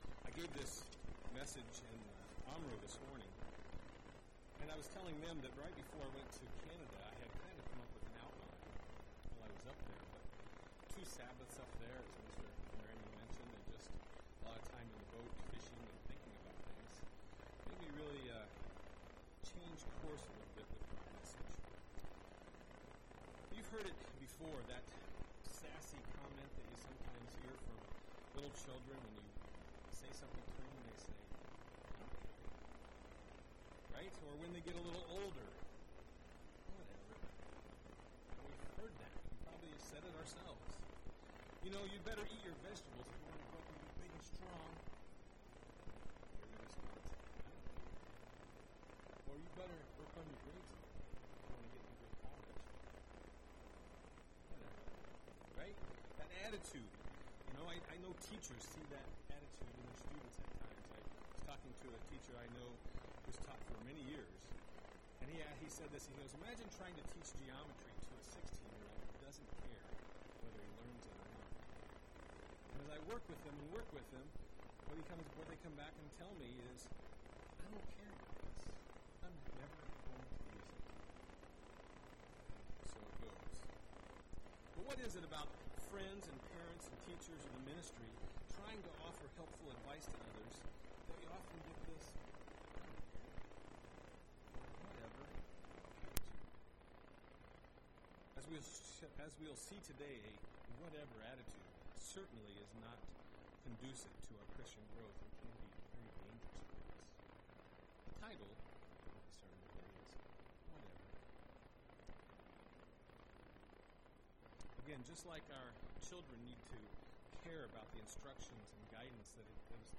Given in Milwaukee, WI
UCG Sermon contentment Complacency zeal attitude Studying the bible?